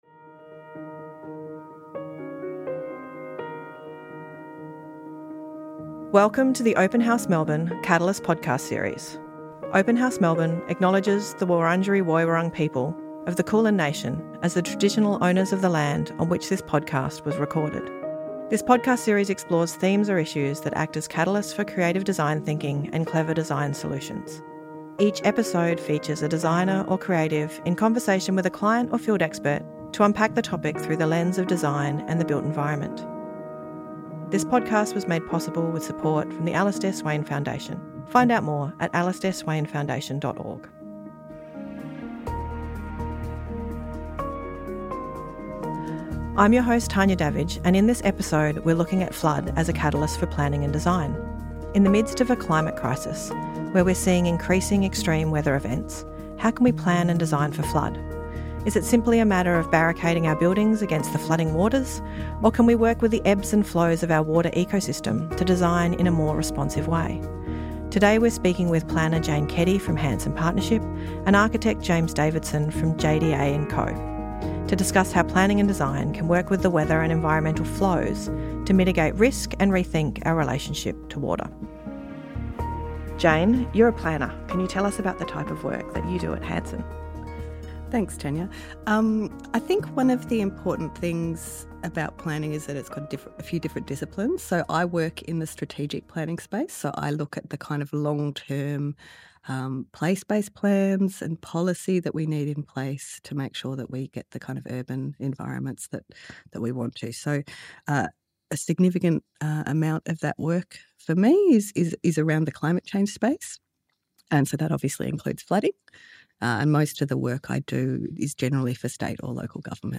The Catalyst podcast series was recorded at The Push , Collingwood Yards.